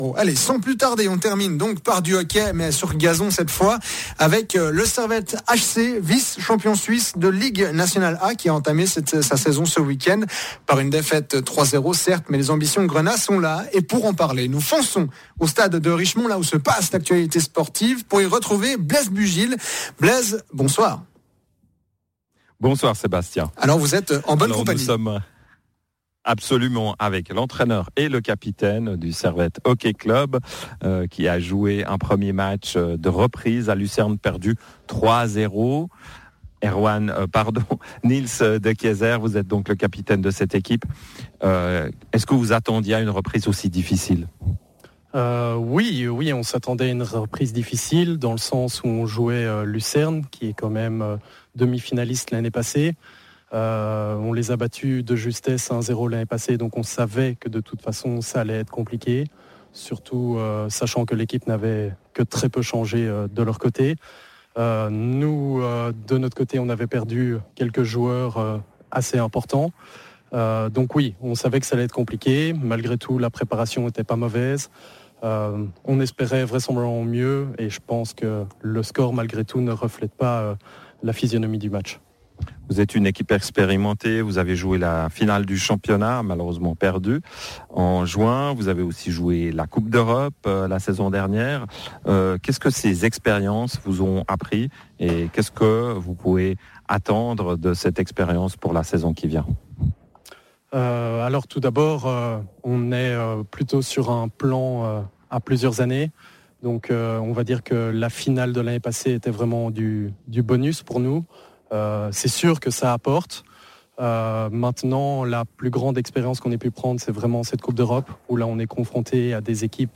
Interview
En direct du stade de Richemont